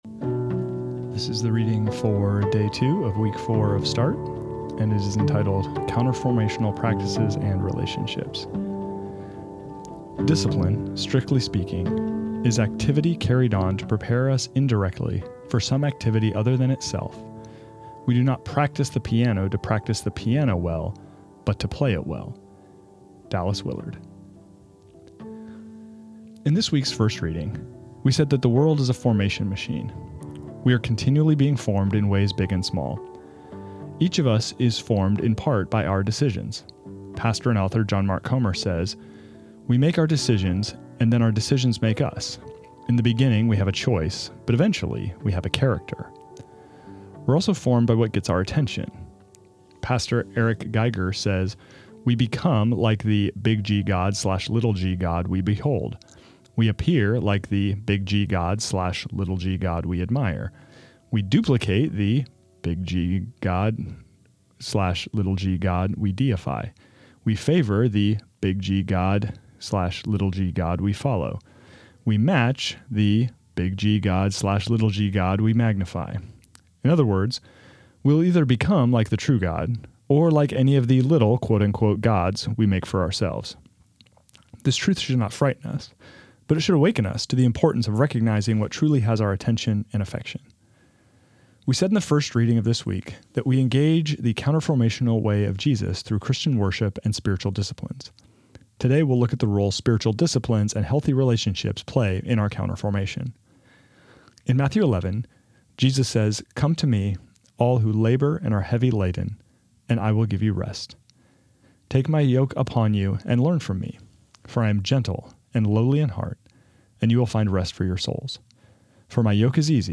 This is the audio recording of the second reading of week six of Start, entitled Counter Formational Practices and Relationships.